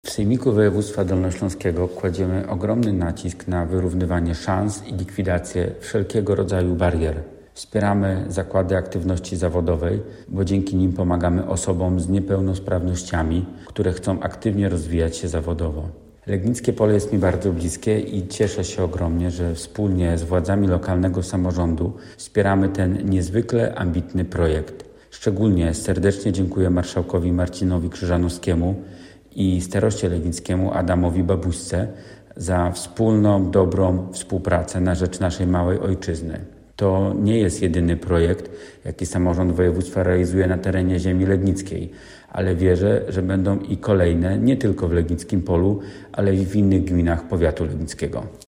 To ambitny projekt, za który dziękuję wszystkim zaangażowanych w to dzieło, mówi Piotr Karwan, radny sejmiku.